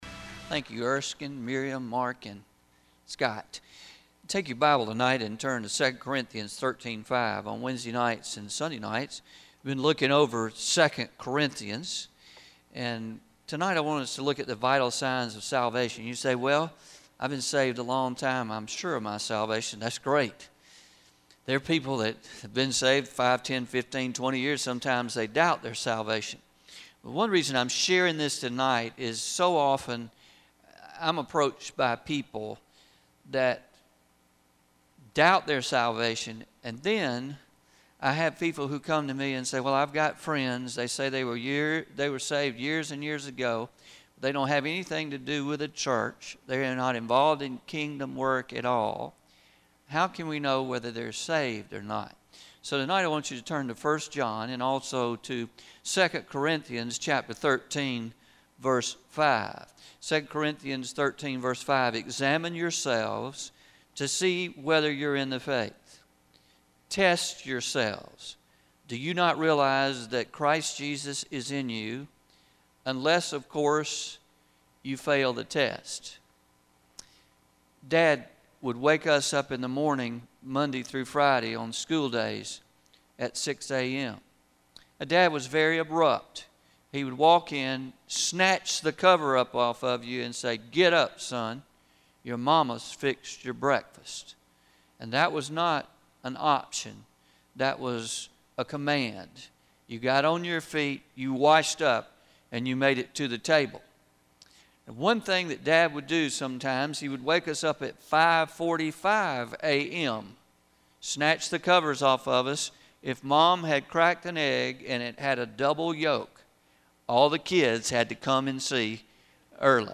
11-10-19pm Sermon – Vital Signs of Salvation